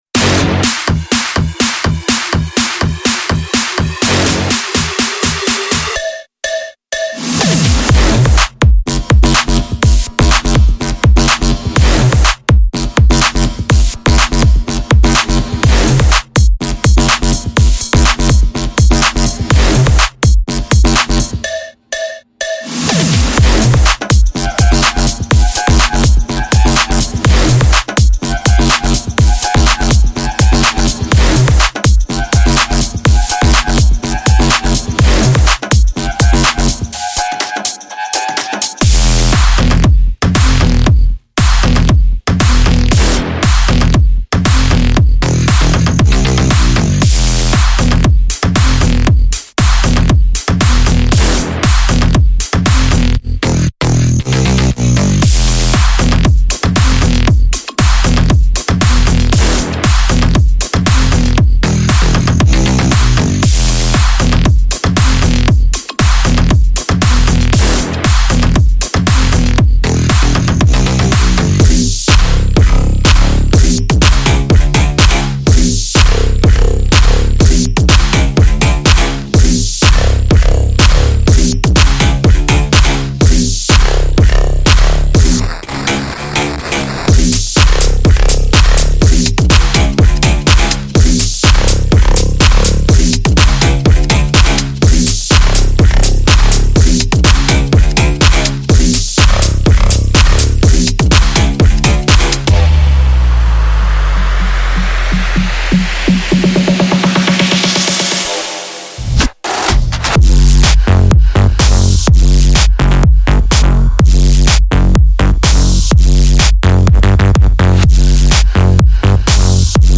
它具有1200多个踢，圈套器，命中，单发，效果，循环，填充，崩溃，打开/关闭的帽子，游乐设施，foleys等！
–下降循环
–鼓循环
–合成器循环